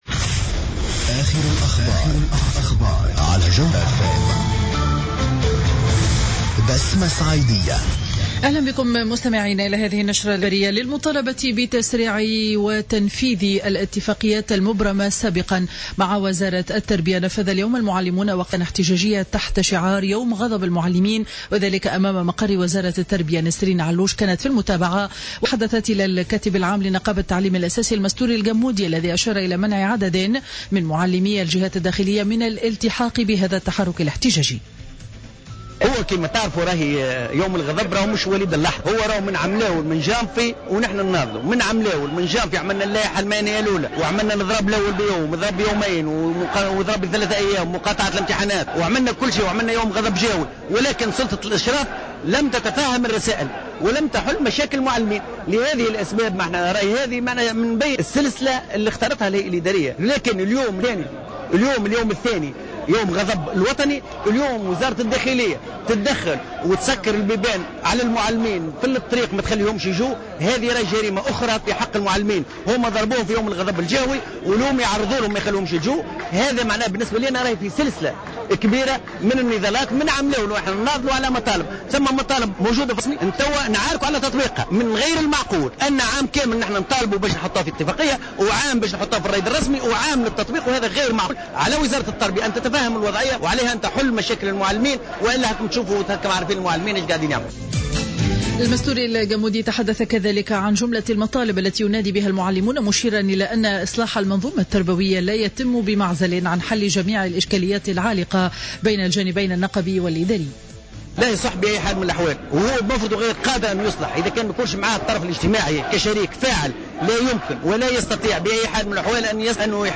نشرة أخبار منتصف النهار ليوم الجمعة 11 سبتمبر 2015